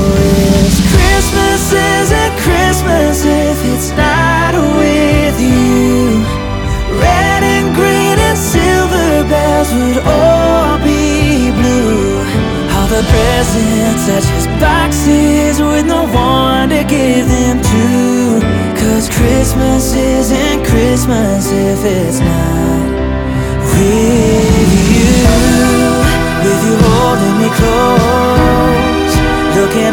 • Holiday
Country music